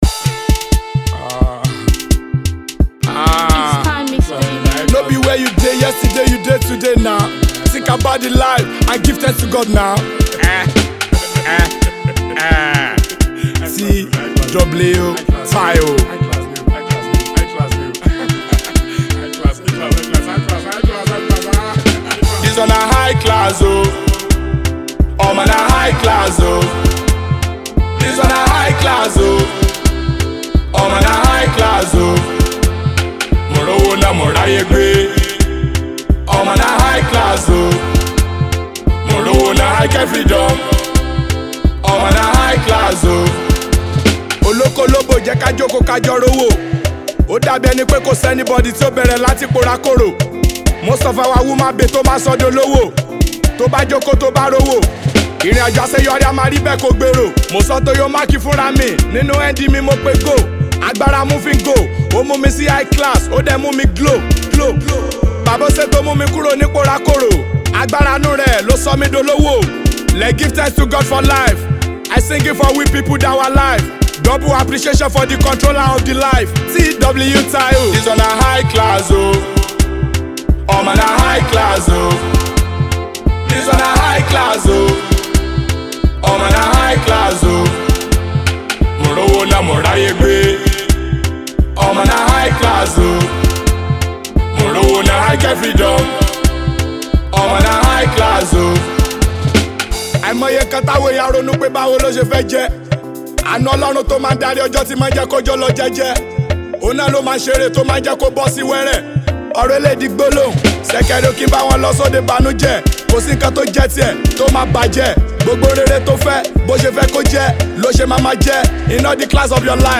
Afro-fusion
Driven by energetic production and infectious flows